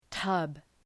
Προφορά
{tʌb}